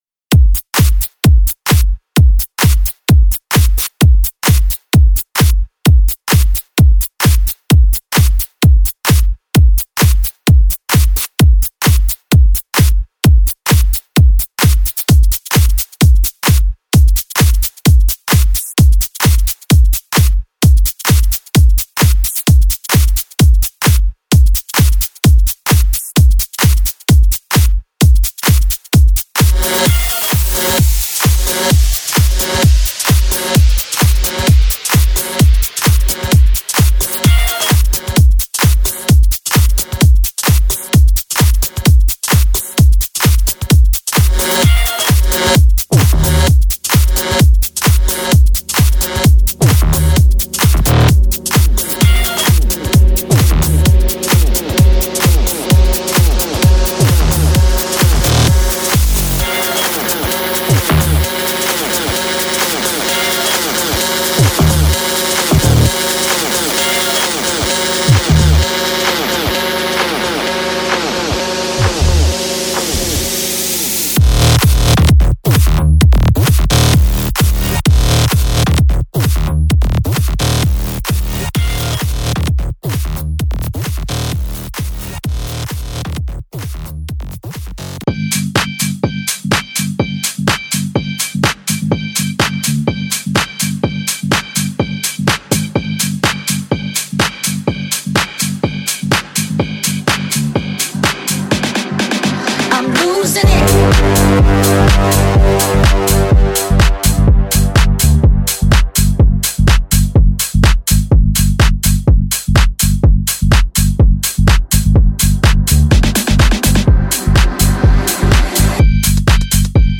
Tech House – (103 samples) Future Bass- ( 84 Samples ) Dance/House – (123 Samples)  Dubstep- ( 153 Samples ) EDM SFX- (73 samples) Kick Drum Loops – (20 Samples) – This Pack is loaded with Snares, Claps, Kicks, Hi Hats& Percussion, Sound Effects, Risers, Swooshes and more! Check out actual samples used in the audio preview below.
EDM-ESSENTIAL-DRUM-AUDIO-PREVIEW.mp3